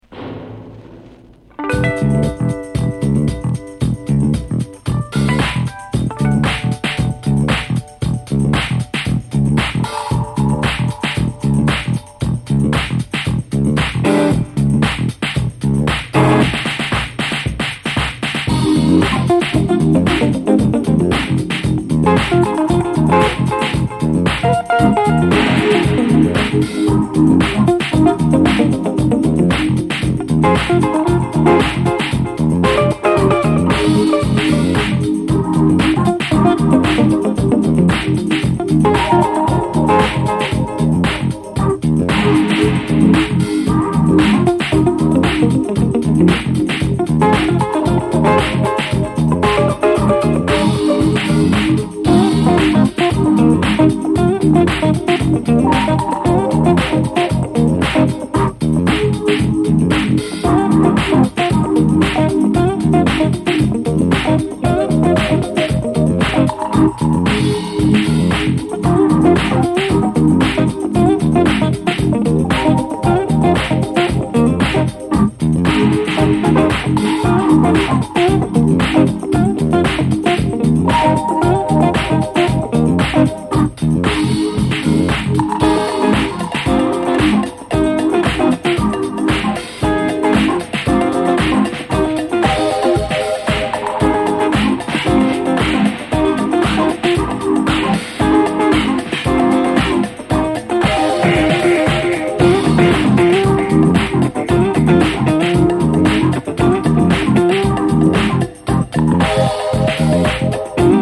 ジャズ・ファンク〜 ディスコ等あらゆる ブラックミュージックを昇華した新世代ダンスミュージック！